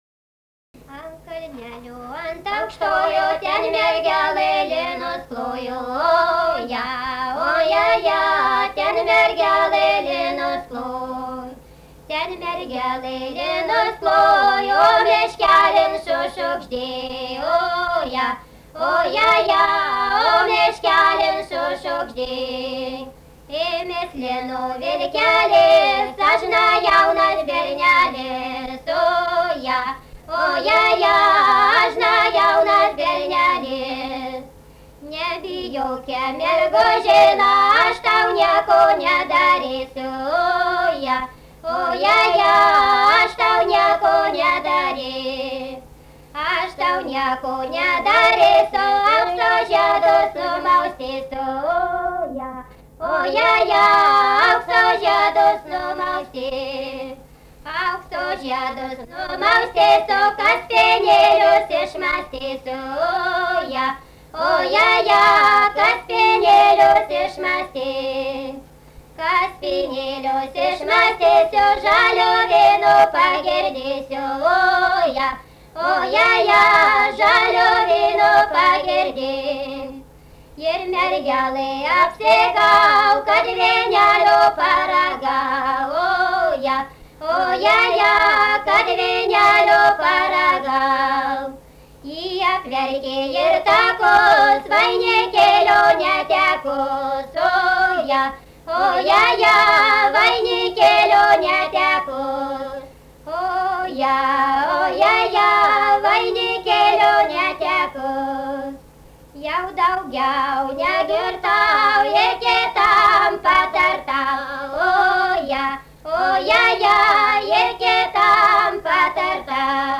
daina
Mardasavas
vokalinis